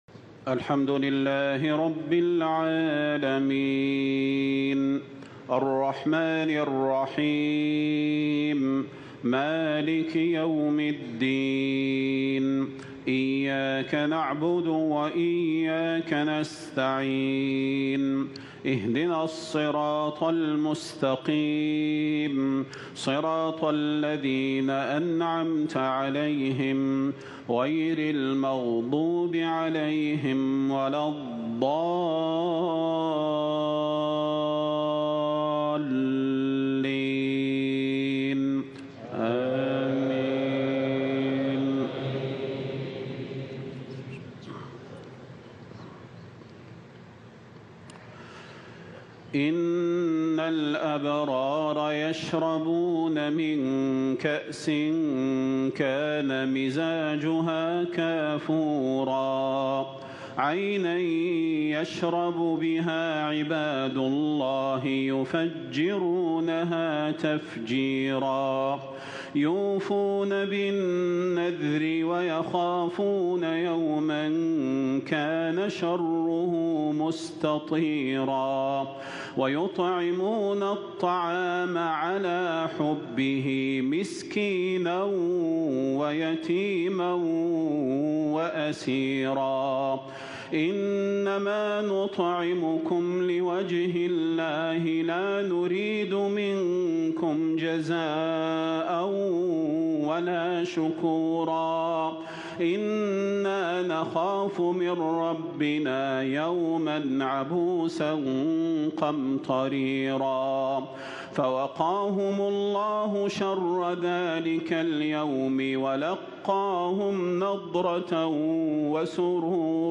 صلاة الجمعة للشيخ صلاح البدير من جامع نغارا بدولة ماليزيا 14 صفر 1447هـ > زيارة الشيخ صلاح البدير لـ دولة ماليزيا > تلاوات و جهود الشيخ صلاح البدير > المزيد - تلاوات الحرمين